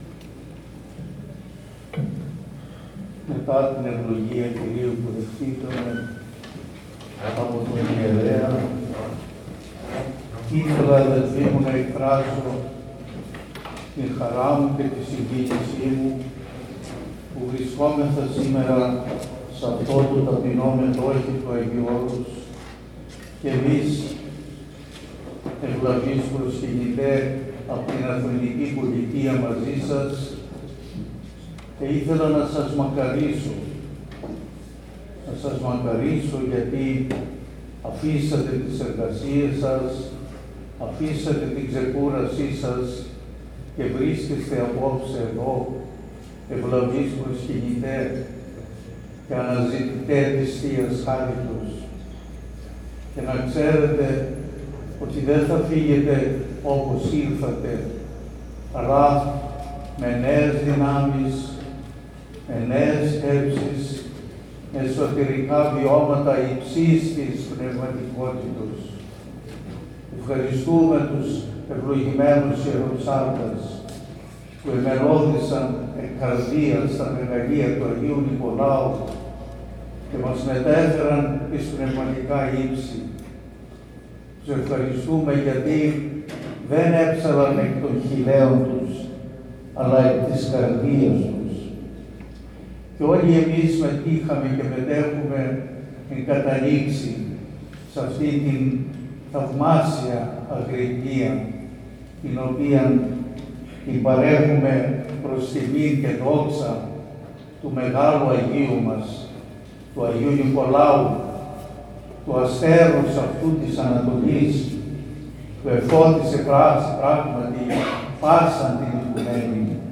Ὁμιλία
Μὲ ἰδιαίτερη λαμπρότητα ἑορτάσθηκε ἡ μνήμη τοῦ ἐν ἁγίοις Πατρὸς ἡμῶν Νικολάου, Ἀρχιεπισκόπου Μύρων τῆς Λυκίας, τοῦ θαυματουργοῦ στὸ Ἱερὸ Μετόχιο τῶν Ὁσίων Ἁγιορειτῶν Πατέρων, τῆς Ἱερᾶς Μονῆς Ξενοφῶντος Ἁγίου Ὄρους (Φιλικῆς Ἐταιρείας 22, Νέα Ἰωνία).